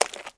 STEPS Swamp, Walk 20.wav